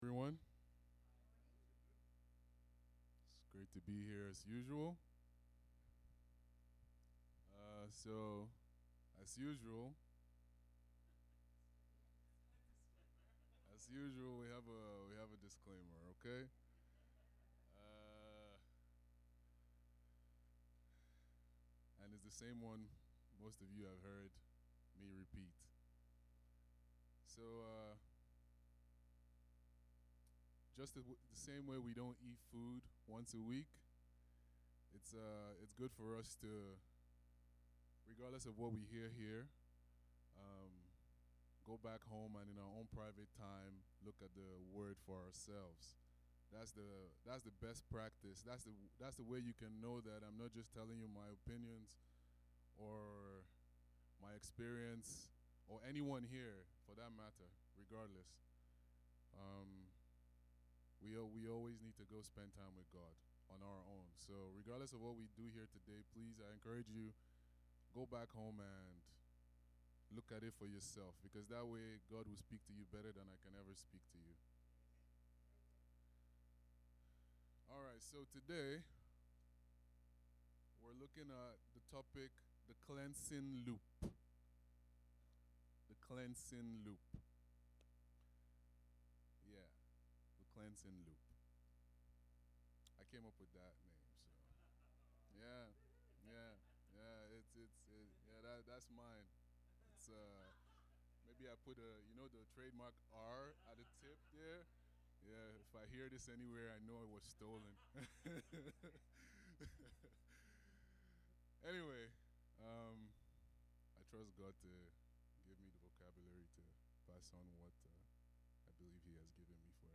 Stand Alone Sermons